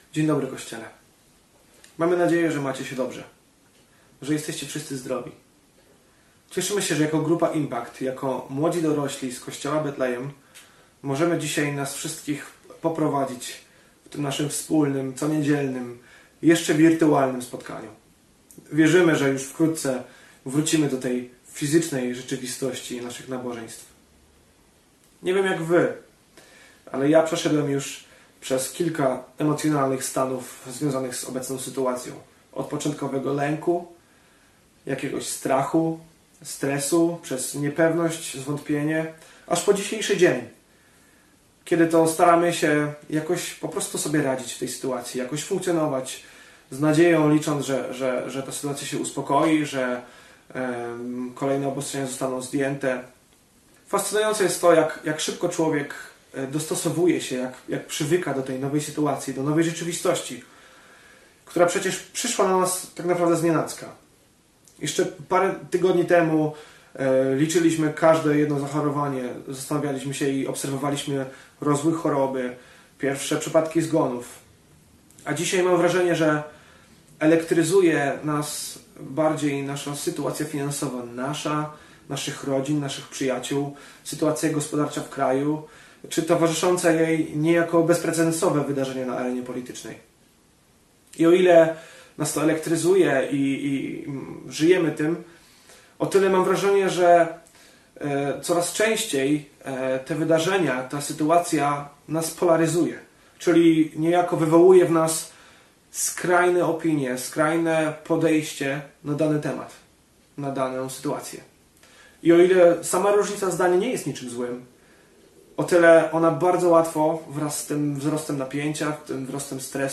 Modlitwa po kazaniu